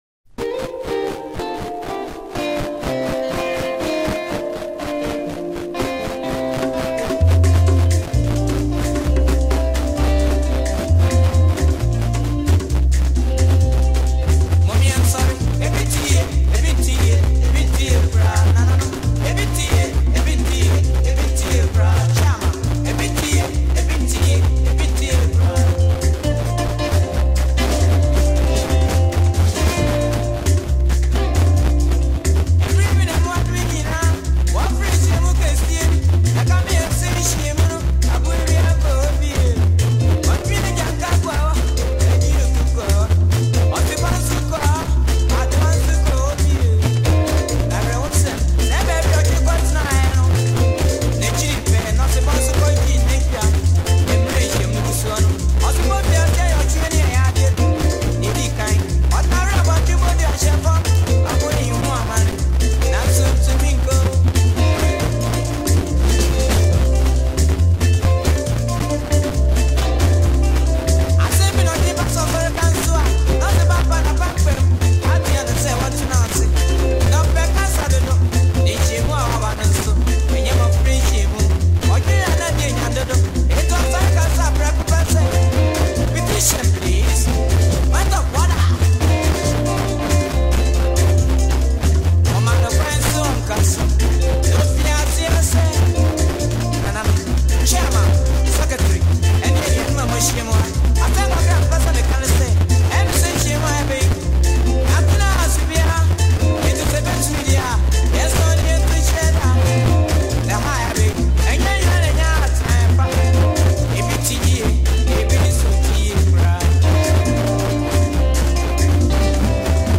captivating Ghana Highlife song